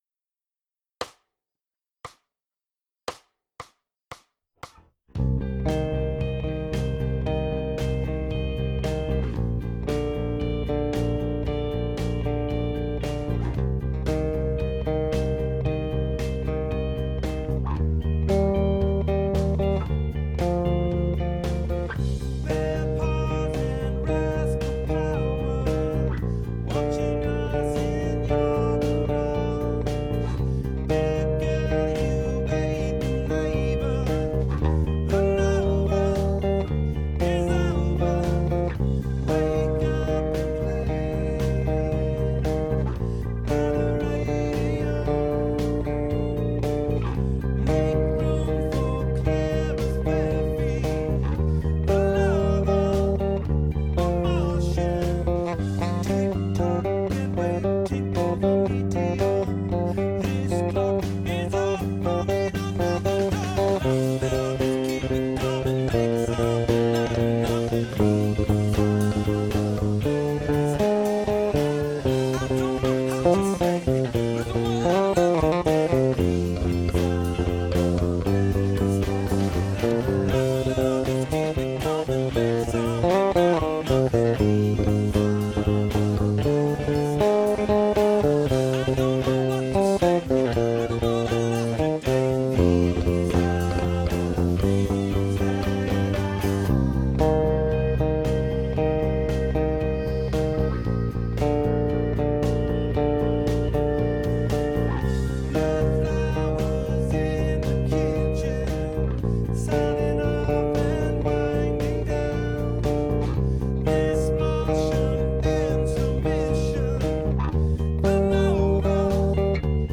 Instrumental / Bass only